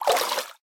assets / minecraft / sounds / entity / fish / swim1.ogg
swim1.ogg